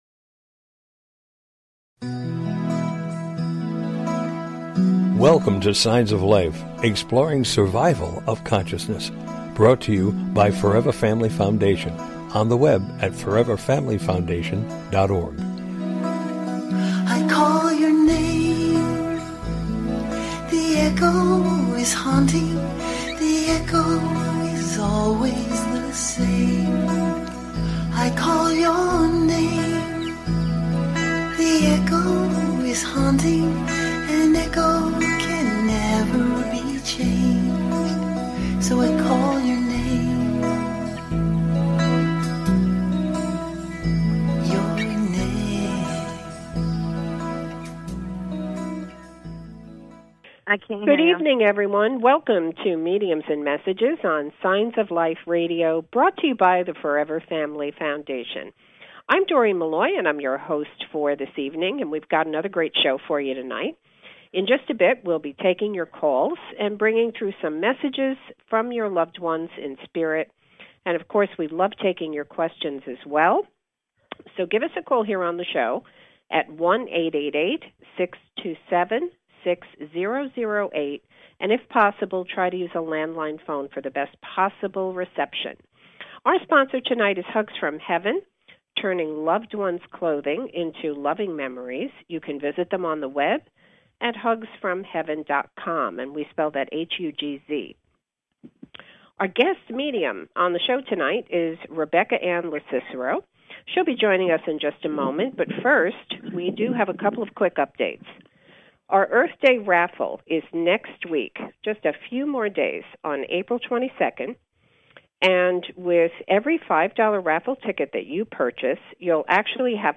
Interviewing guest medium